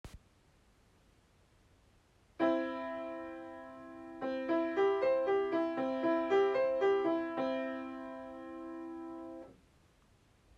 音源①：ダンパーペダルを踏みっぱなしにしたヴァージョン
音源②：2段目のドとミの和音にのみソステヌートペダルを踏んだヴァージョン
音源①は全体的に音が響いて聞こえていたと思いますが、音源②ははじめに弾いたドとミの和音のみ響いていませんでしたか？